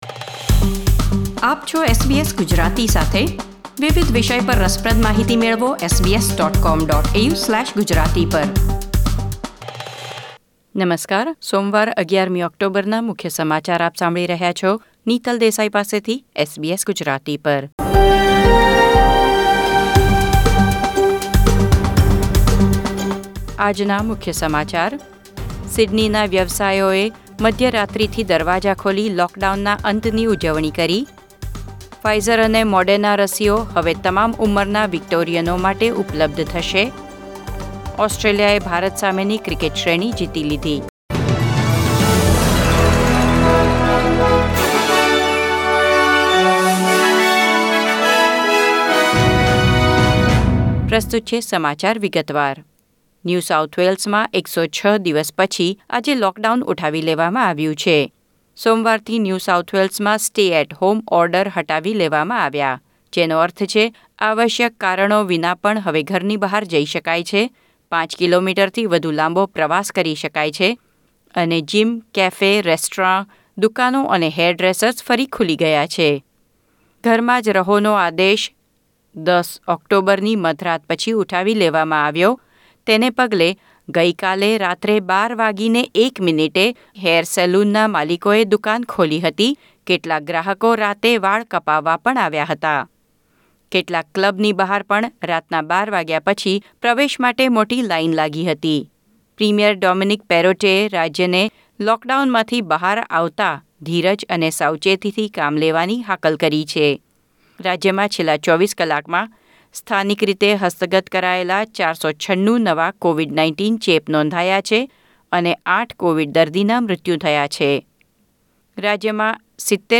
SBS Gujarati News Bulletin 11 October 2021